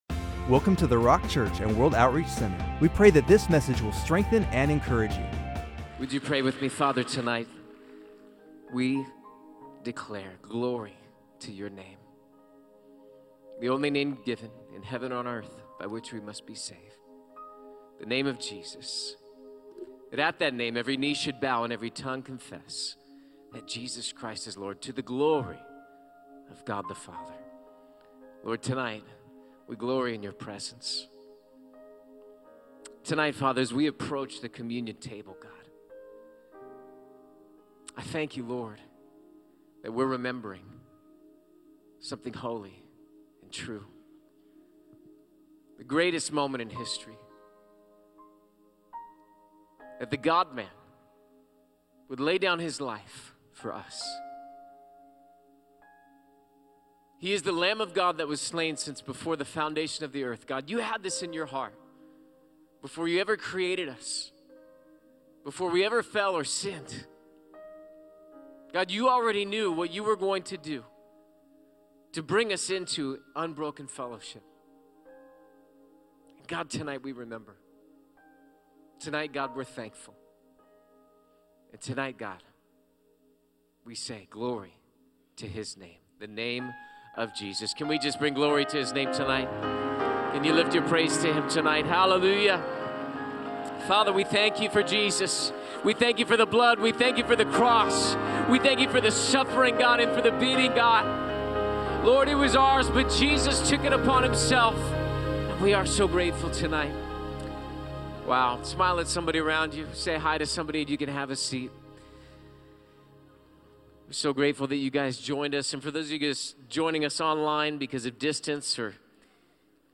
Good Friday Communion Service